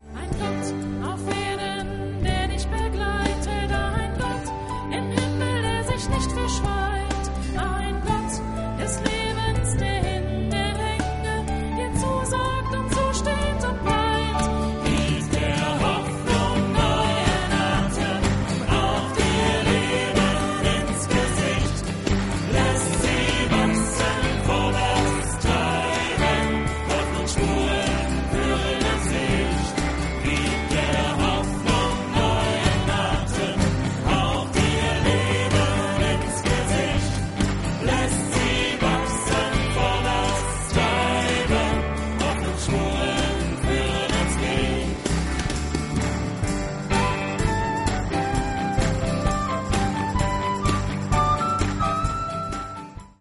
akk. Gitarren, Gesang
Flöte , Gesang
Key, Gesang
Western-Gitarre
E-Gitarre
Bass
Drums